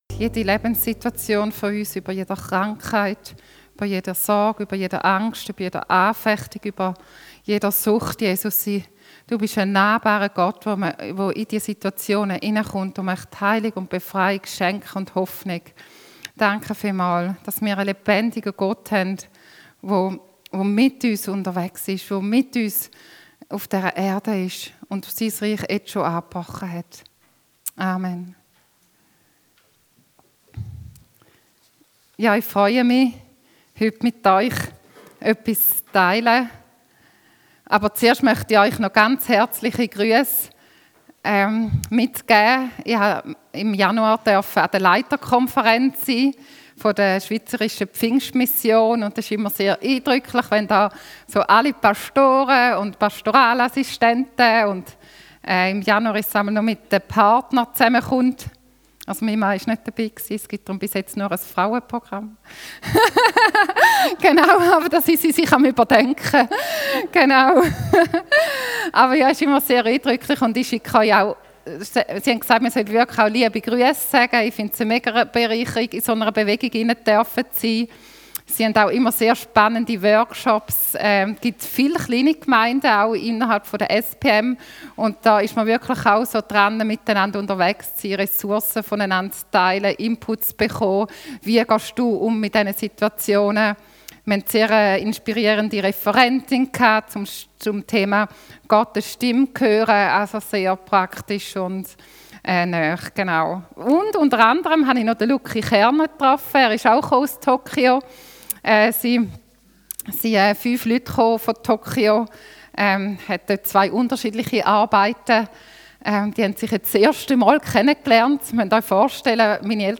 Johannes Dienstart: Gottesdienst Themen: Brot des Lebens « Jahreslosung 2025 1 Thessalonicher 5